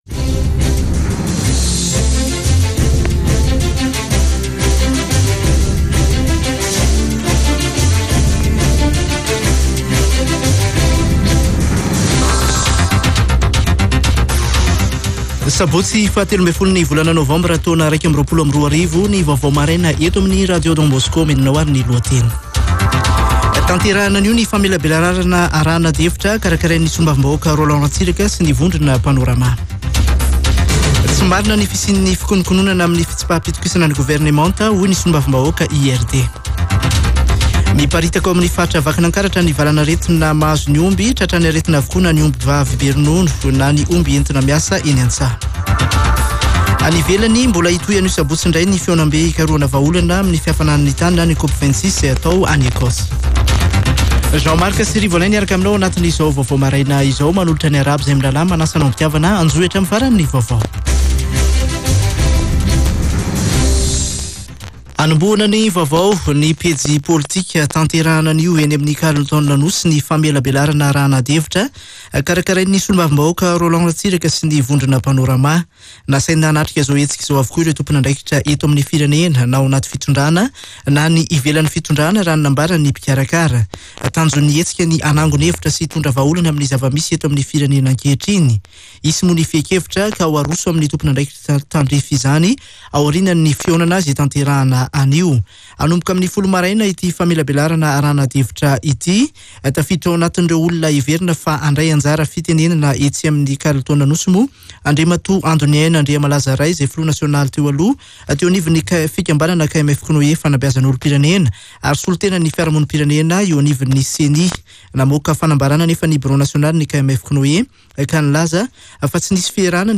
[Vaovao maraina] Sabotsy 13 novambra 2021